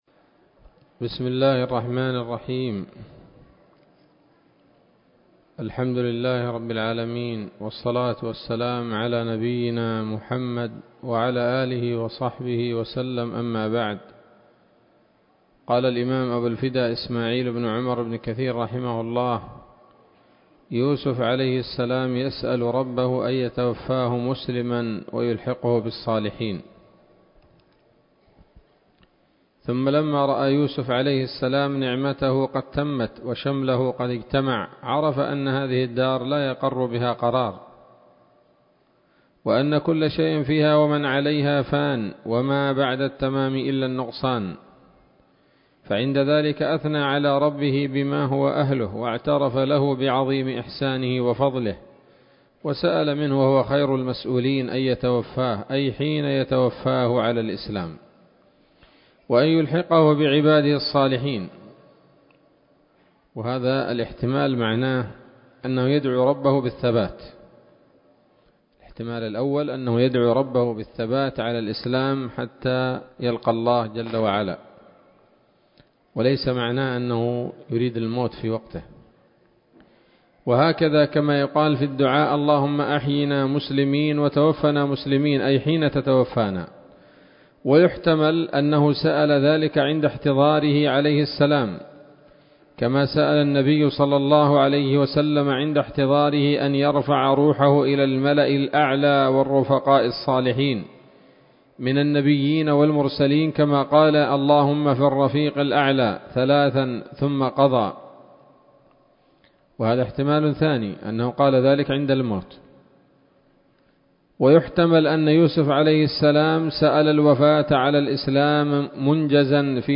الدرس الثالث والسبعون من قصص الأنبياء لابن كثير رحمه الله تعالى